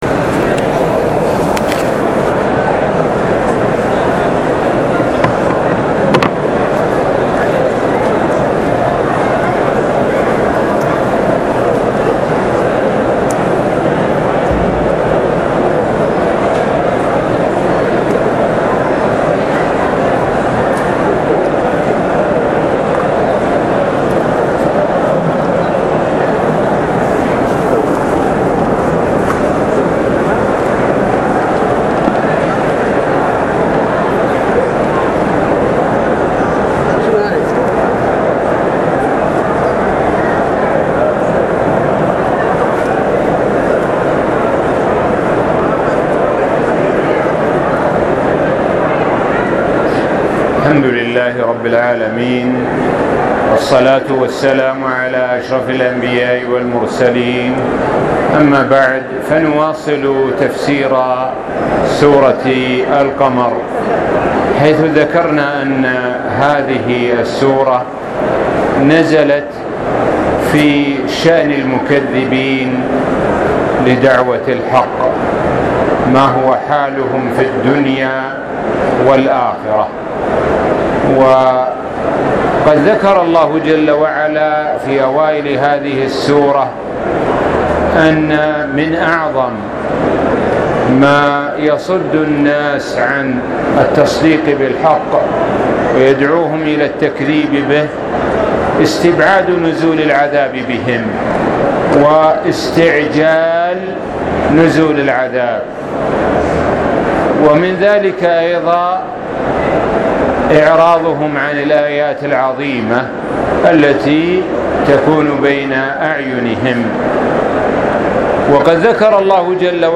الدرس السادس عشر : سورة القمر (18-32) السابق التالى play pause stop mute unmute max volume